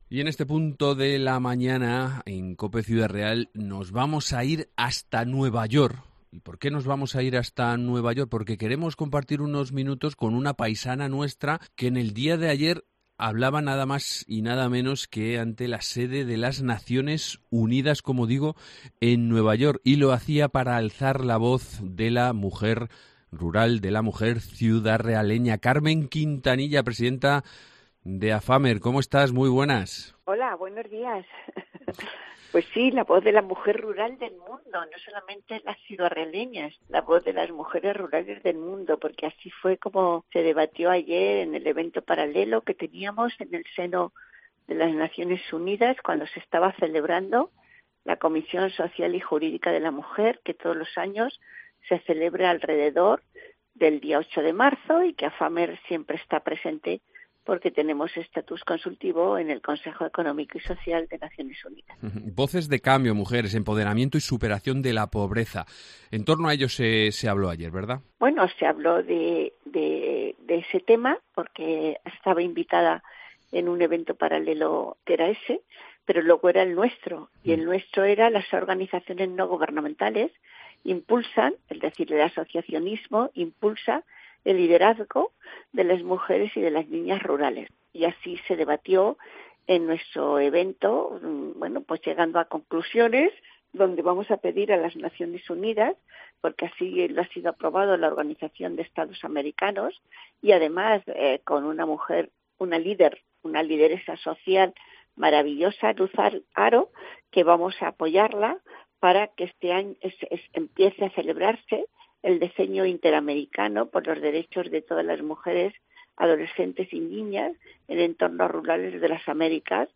Entrevista con Carmen Quintanilla, presidenta de Afammer